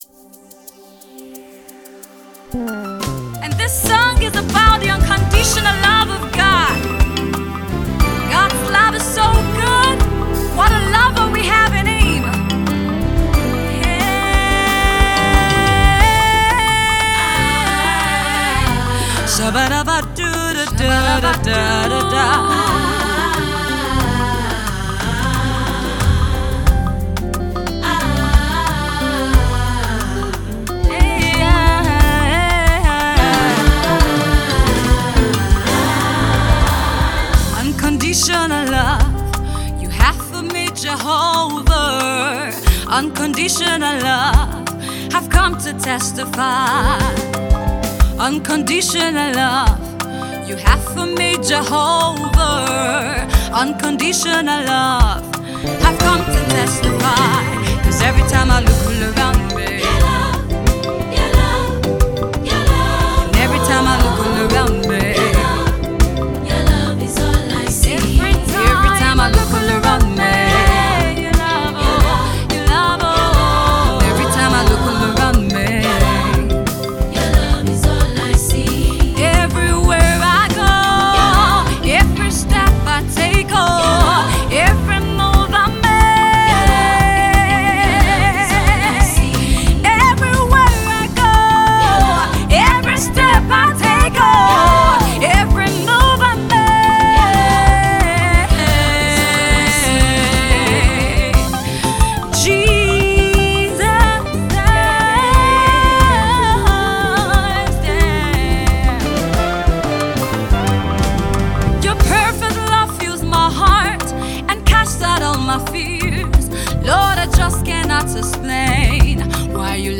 Nigerian gospel music minister and songwriter